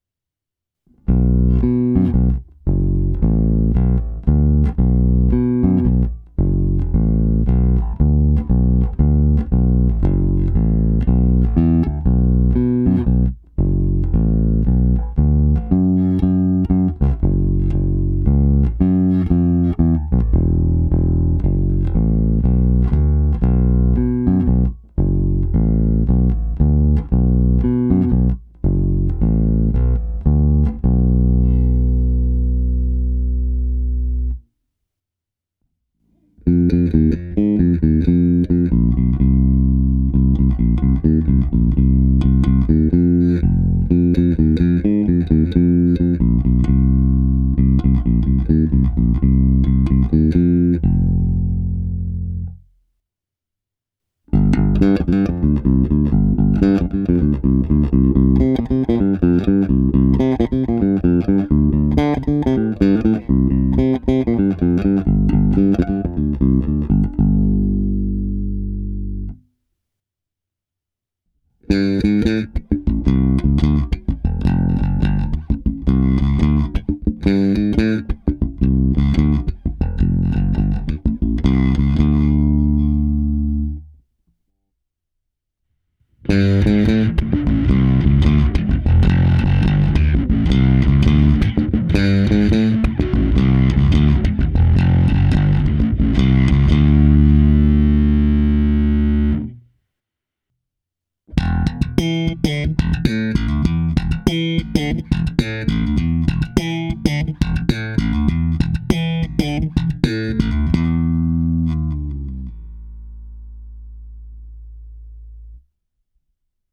Poslední ukázka je kombinace 50/50 čistého zvuku baskytary a simulace aparátu pomocí preampu Darkglass Alpha Omega Ultra s předřazeným kompresorem TC Electronic SpectraComp.
Ukázka se simulací aparátu v pořadí: krkový snímač, oba snímače, kobylkový snímač, oba snímače bez a se zkreslením, slap na oba snímače.